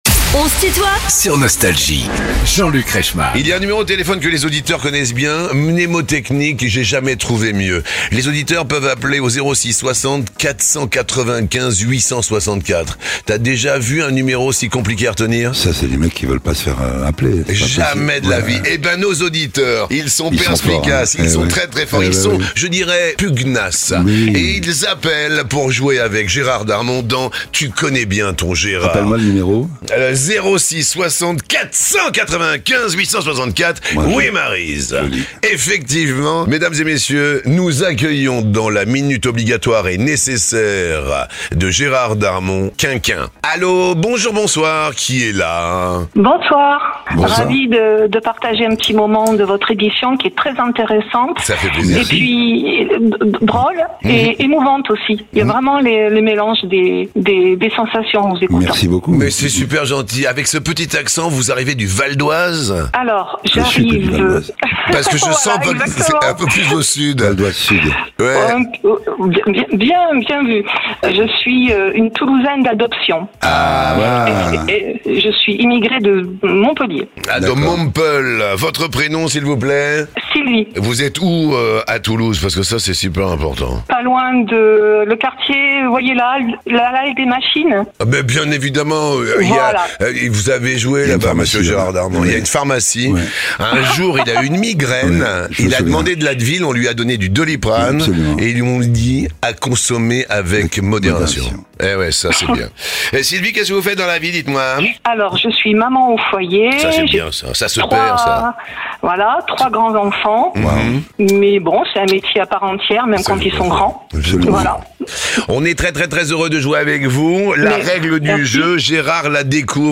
Gérard Darmon est l'invité de "On se tutoie ?..." avec Jean-Luc Reichmann